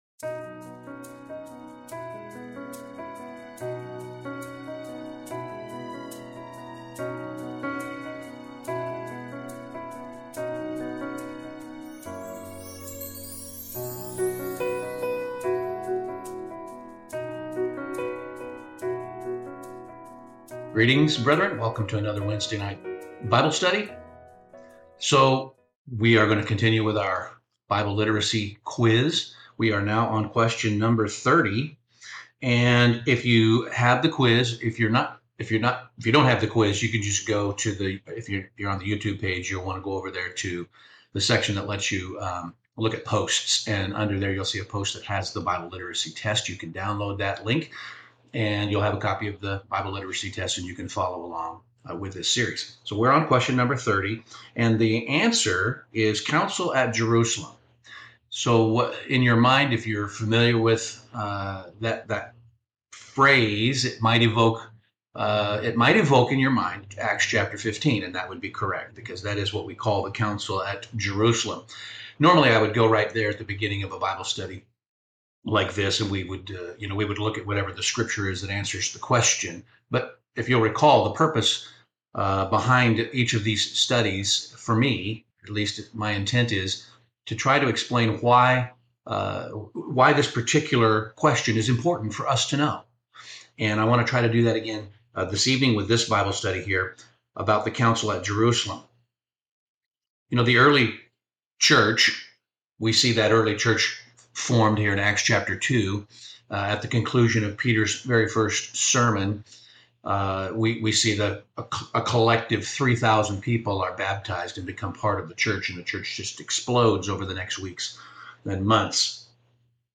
Acts 15 captures one of the most critical moments in Church history—when the very meaning of salvation was challenged and unity hung in the balance. This message walks through the tension, the controversy, and the careful process by which the early Church confronted a question it could not ignore.